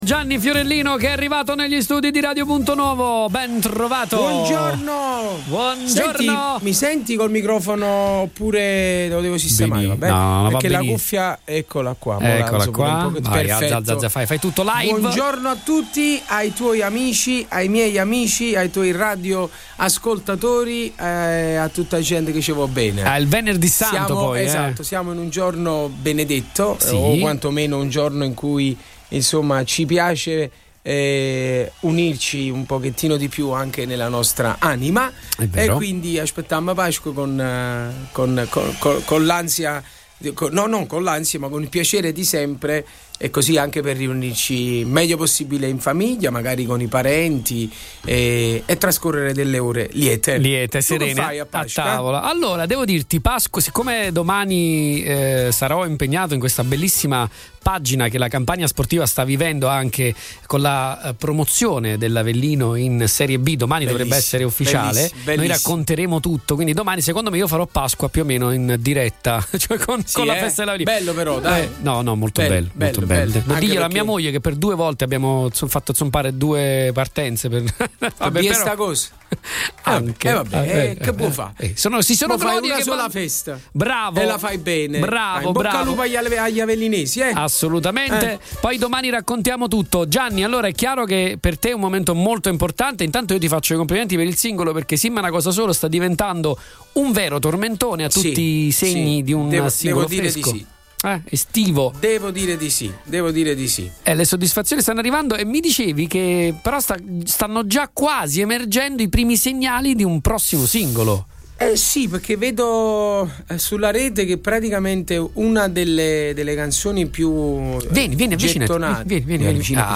Gianni Fiorellino negli studi di Radio Punto Nuovo annuncia il “Vai Tour”!
Un’energia contagiosa ha invaso gli studi di Radio Punto Nuovo con l’arrivo di Gianni Fiorellino, un artista che non ha bisogno di presentazioni!